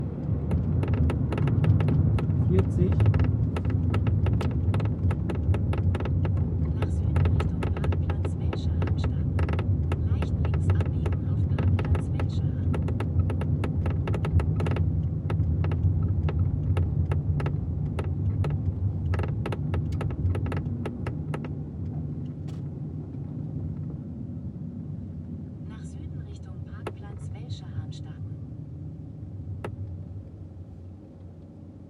Skoda Fabia 2 Kombi Geräusche hinten links
Es hört sich an wie ein Tischtennisball, der ständig hüpft. Also mit Wind und Geschwindigkeit hat es nichts zu tun.
Ich habe eine Aufnahme angehängt bei 40 km/h.
Also ich habe mal kräftig beim parkenden Auto am Stoßdämpfer gerüttelt. Es ist genau das Geräusch.
40km_h_Geraeusche_hinten_links_01.mp3